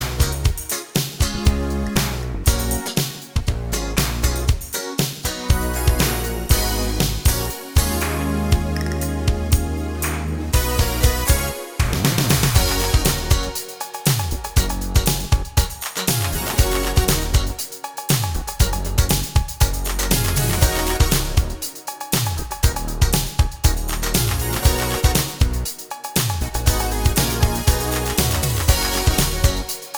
Twofers Medley Pop (1980s) 5:38 Buy £1.50